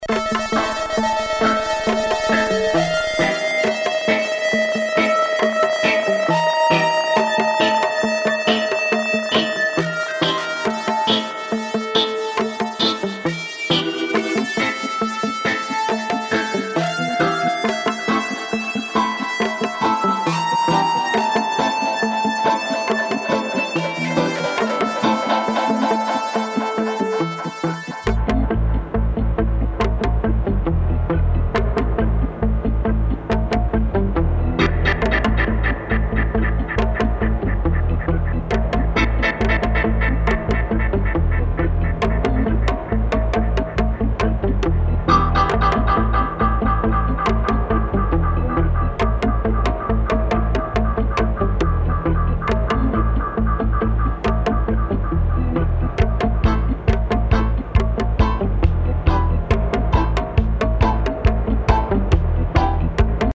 12″ Showcase with Vocals Dubs & Versions.
modern roots steppers
All recorded, mixed & mastered